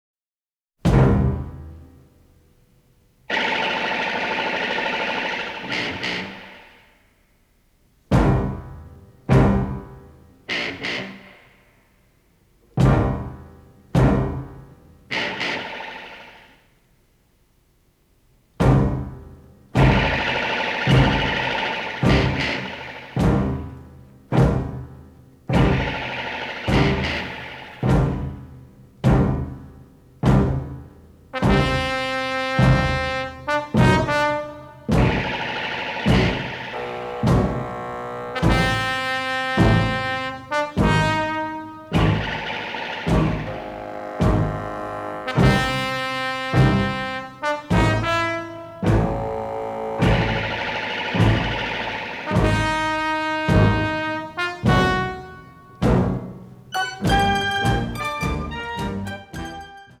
Film Versions (mono)